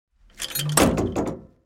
جلوه های صوتی
دانلود صدای تانک 2 از ساعد نیوز با لینک مستقیم و کیفیت بالا